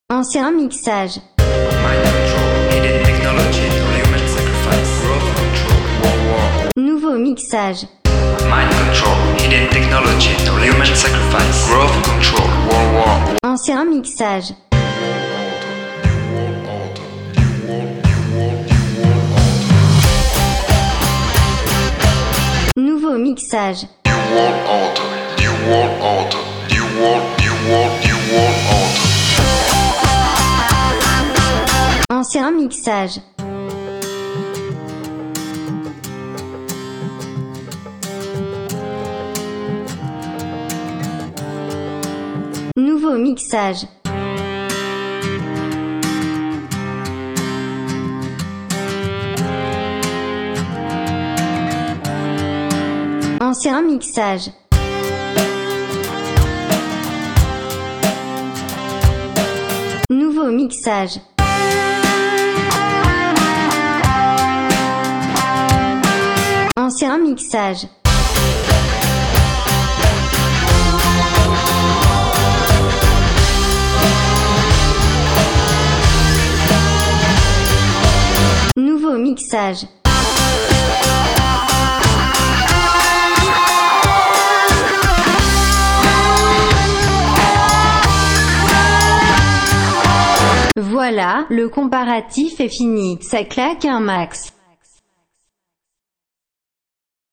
• présence de la voix, des instruments lead et des choeurs
• clarté générale
• profondeur et stéréo
• punch et sonorité de la caisse claire
L’ancien mix, même s’il avait la fougue du “son garage”, sonnait maquette dans une playlist. Le nouveau mix est sur ce plan là un bon cran au dessus niveau qualité à mon goût.